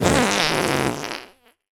Suicide Pop Sound Effect
suicide-pop-2.mp3